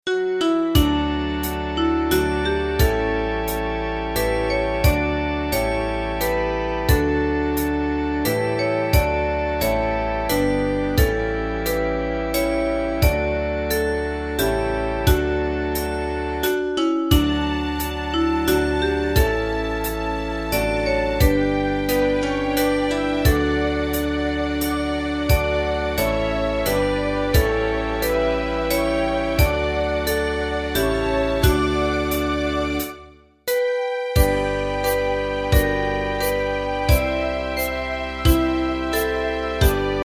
Ensemble musical score and practice for data.